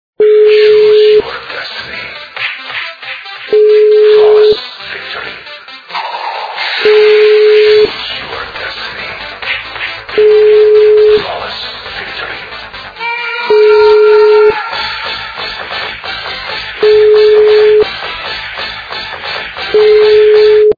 êà÷åñòâî ïîíèæåíî è ïðèñóòñòâóþò ãóäêè.